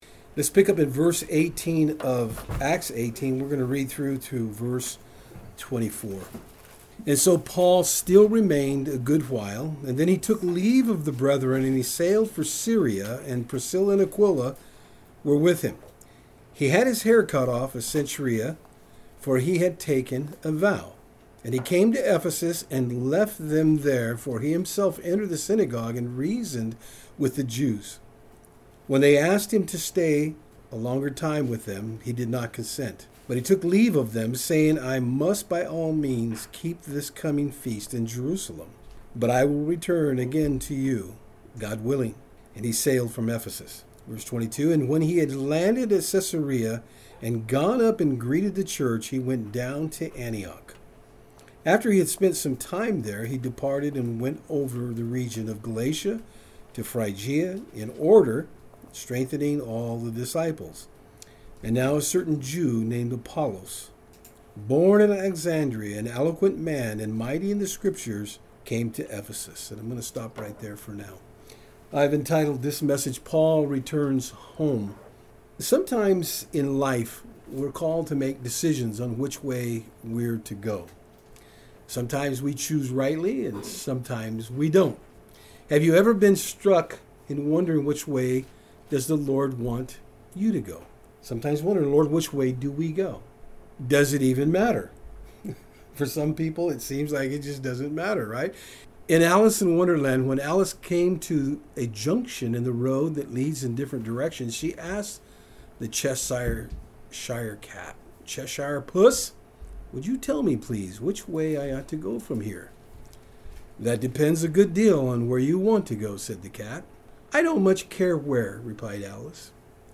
(Background buzz is pellet stove)